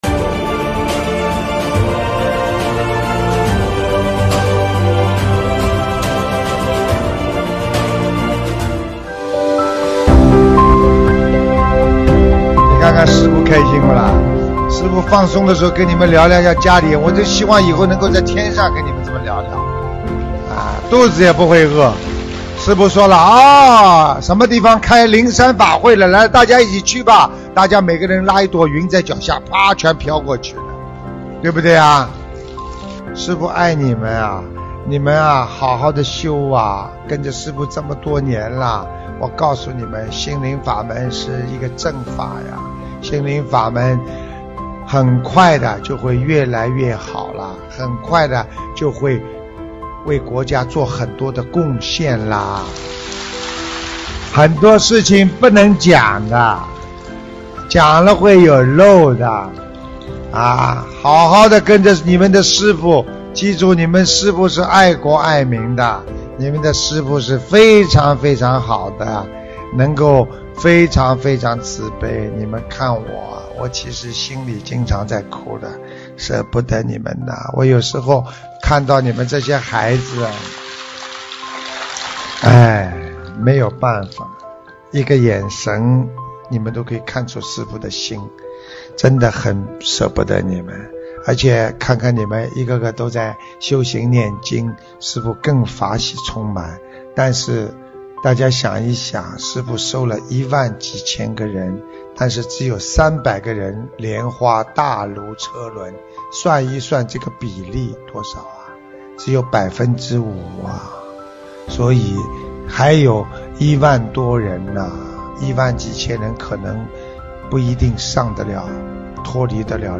音频：缅甸腊戌观音堂开光大典!2022年03月21日